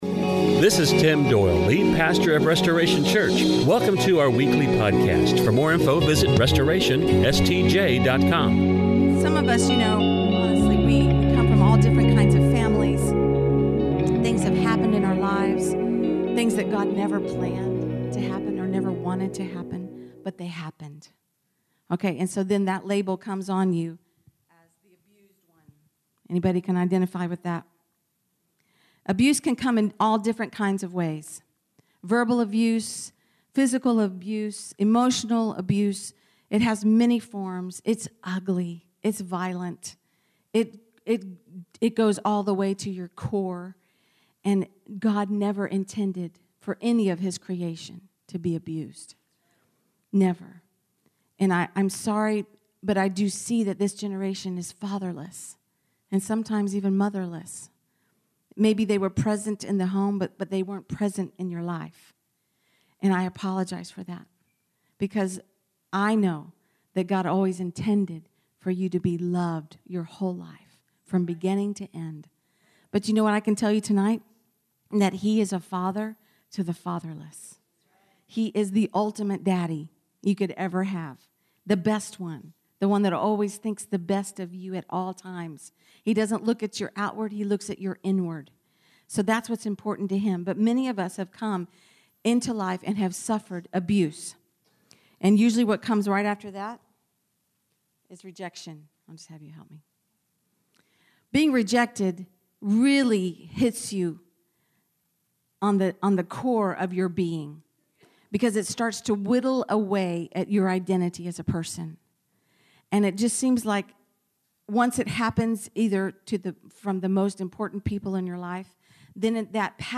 recorded live at Warrior Women: Fall Gathering, Restoration Church, November 16th, 2015.